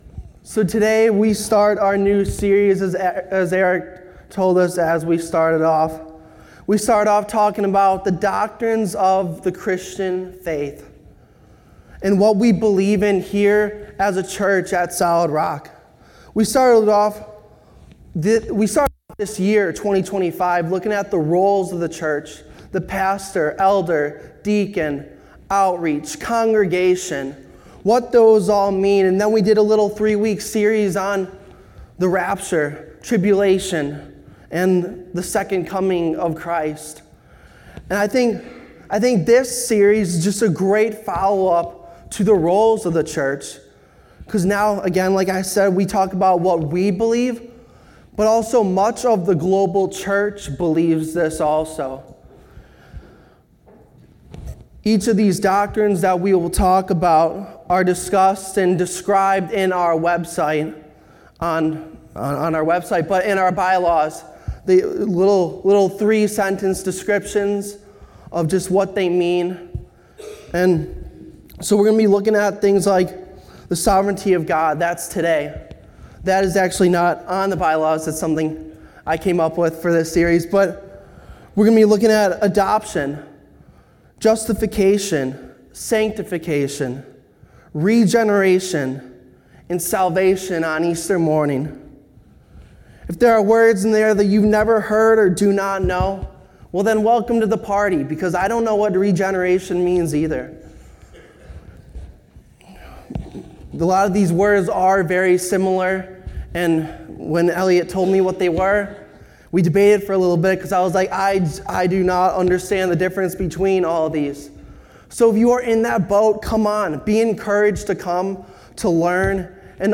Sermons | Solid Rock Church